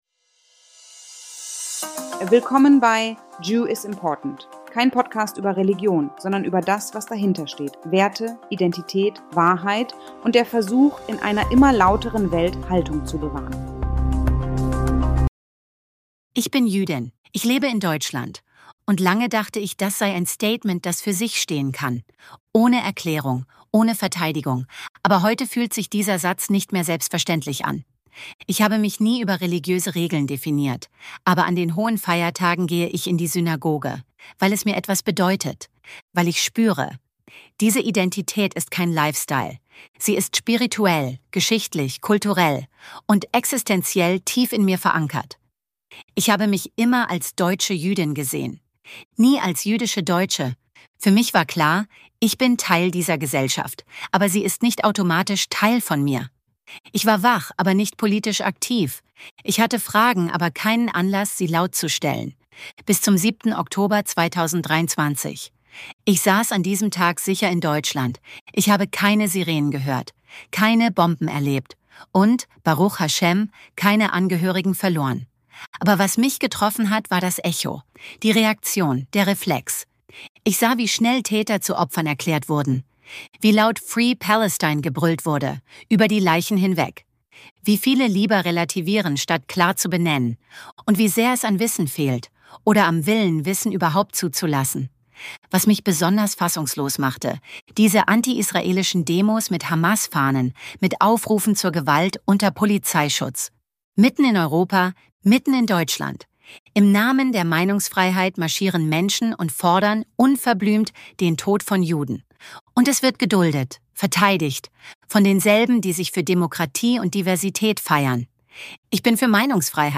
© 2025 AI-generated content. All rights reserved.